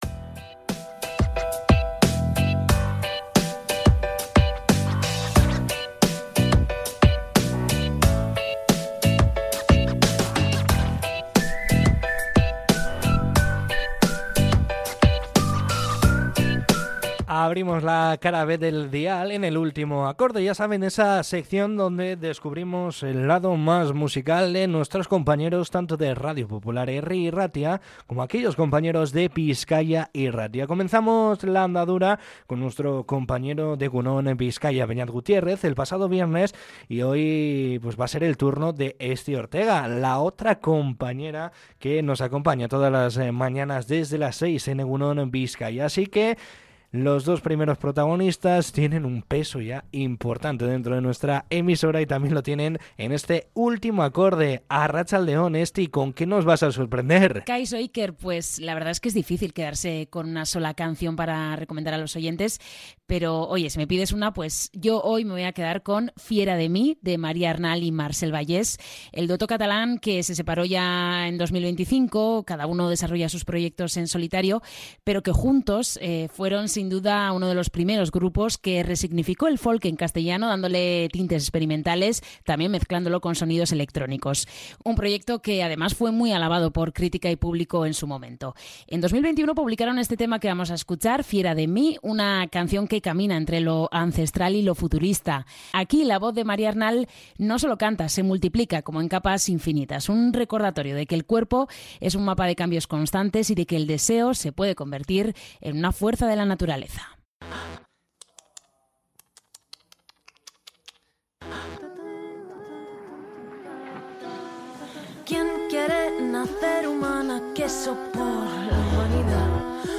En ‘Fiera de mí’, la voz de Maria Arnal no solo canta, sino que se multiplica en «capas infinitas».